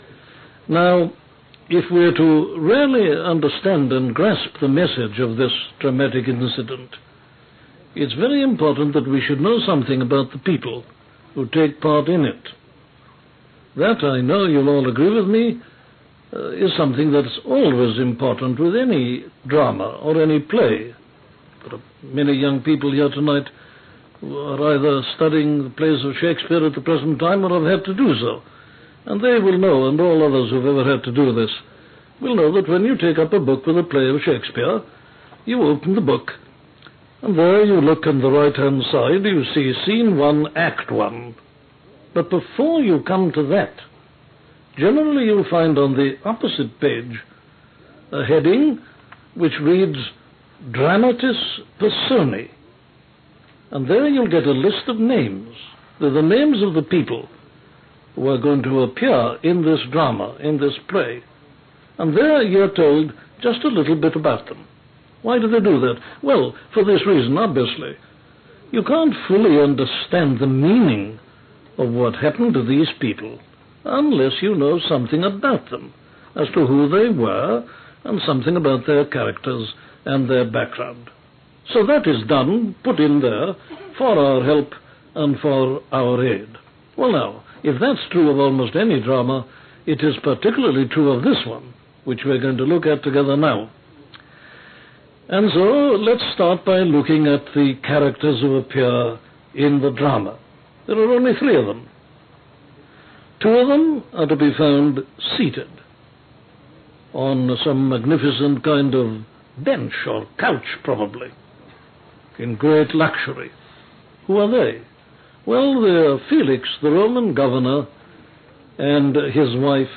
In two minutes and fourteen seconds, Lloyd Jones provides the audience with a rationale for taking the time to do what he is about to do.